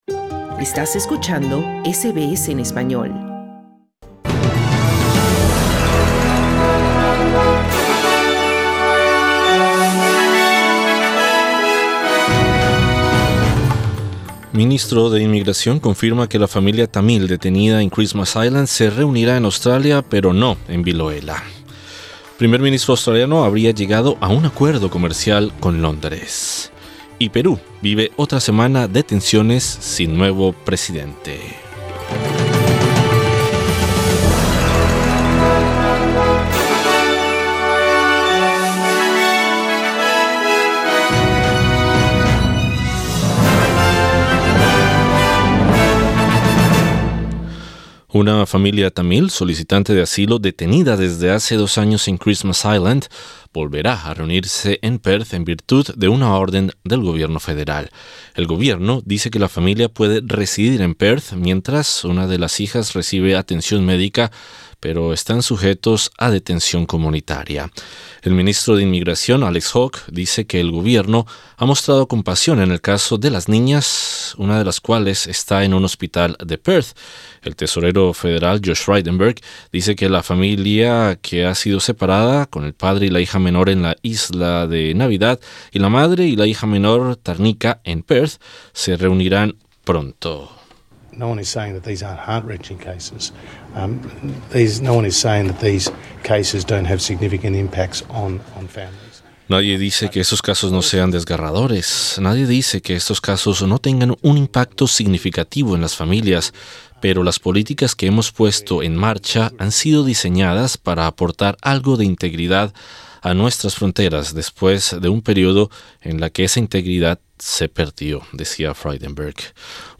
Noticias SBS Spanish | 15 de junio 2021